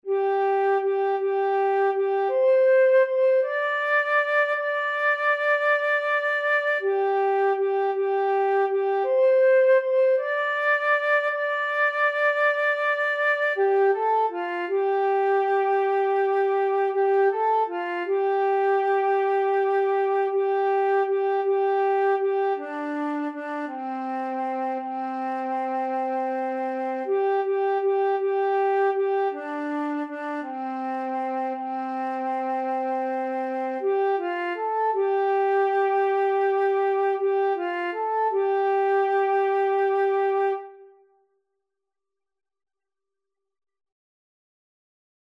Liedjes voor peuters en kleuters
dit liedje is pentatonisch